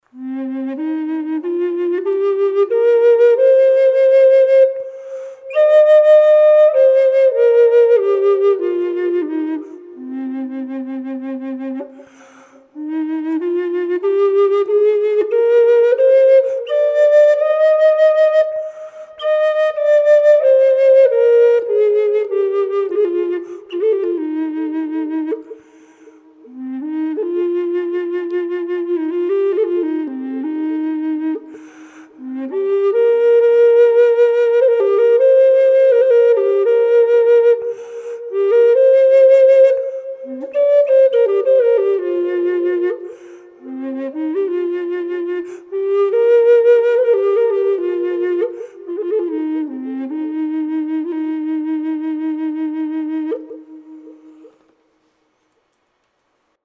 Bass Cm Side-Blown Mesquite Flute W/Spalted Tamarind Accents
This flute has a big, low tone.
mesquite_bass_cm_wet.mp3